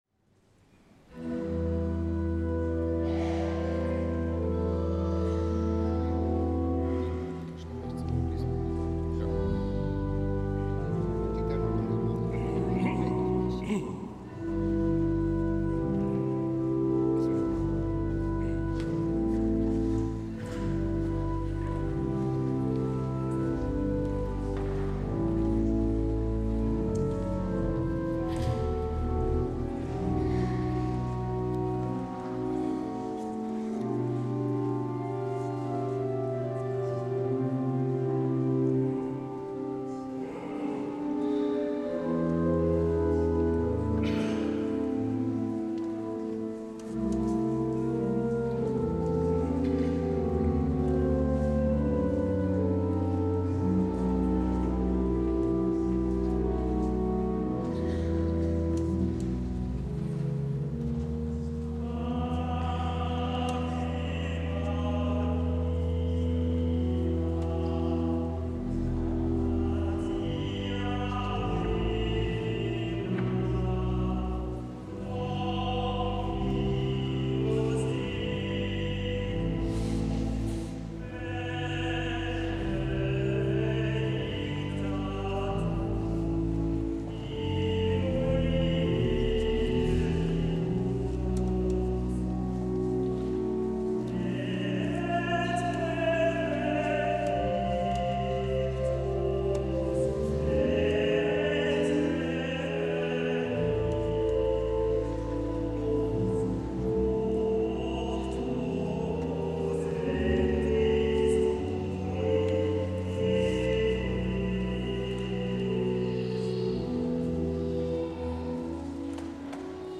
Musikalischer Abschluss der dritten Oktav 2013
Kinder- und Jugendchor
Projektchor
Tenor